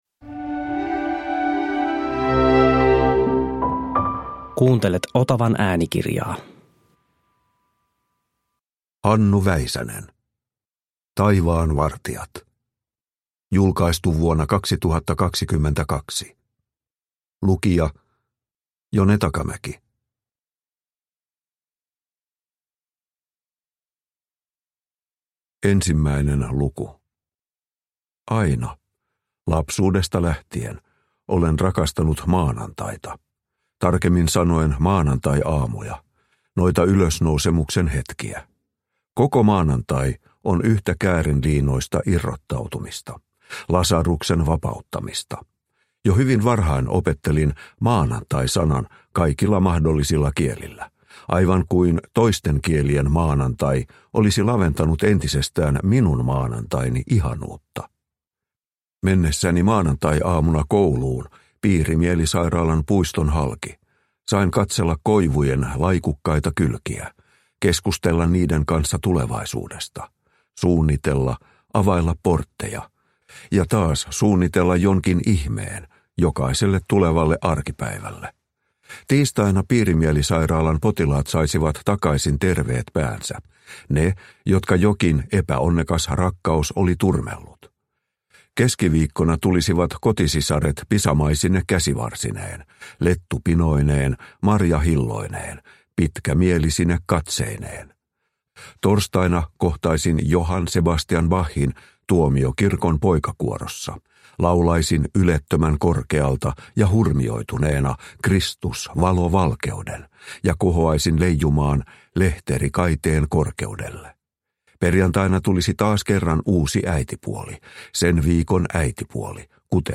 Taivaanvartijat – Ljudbok – Laddas ner